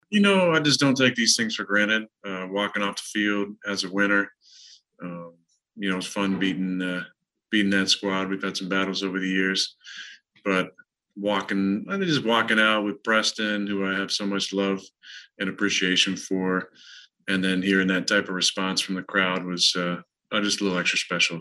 Aaron Rodgers via zoom post game.
Rodgers conducted his post-game media session via zoom, to avoid having to don a mask in front of reporters in the media auditorium.
Speaking in soft, measured tones, Rodgers admitted he was tired, and it was an emotional return to football, so much so, he said he got misty walking off the field with Preston Smith, one of many teammates who constantly checked on his health and well being while he was away: